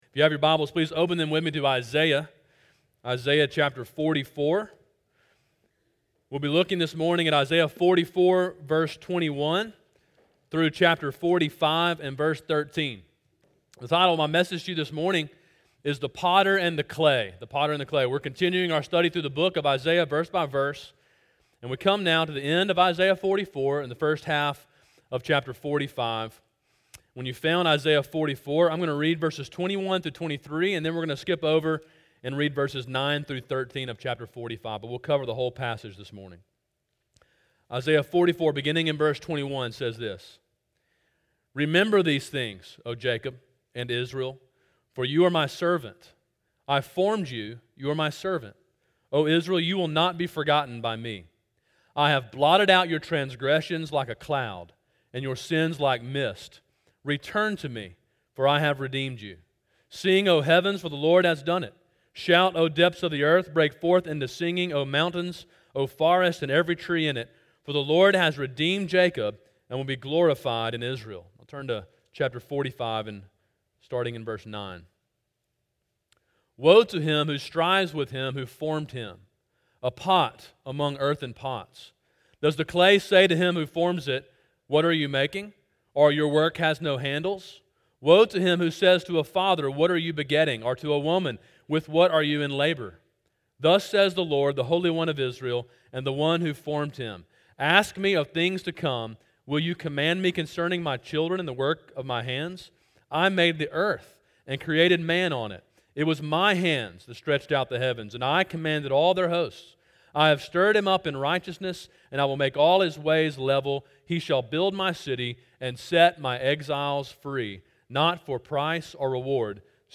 Sermon: “The Potter and the Clay” (Isaiah 44:21-45:13) – Calvary Baptist Church